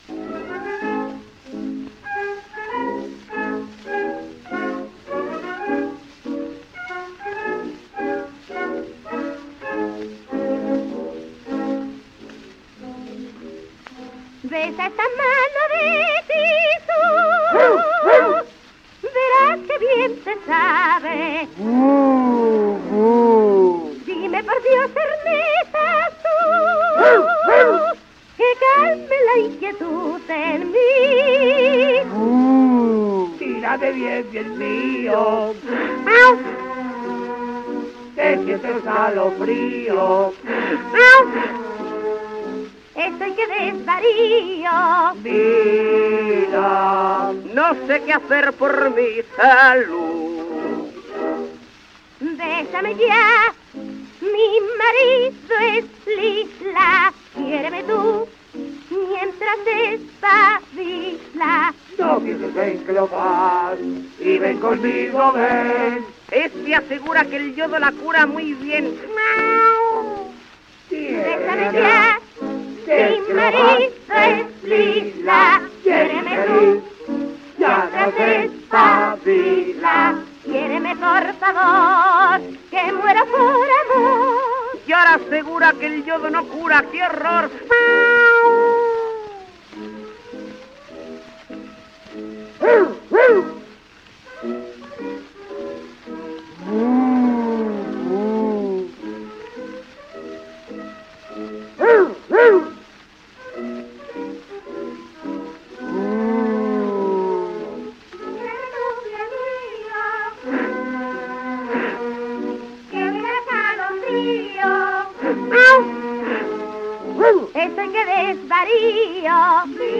Humorada en un acto
Chotis ("Besa esta mano de tisú...").
coro [78 rpm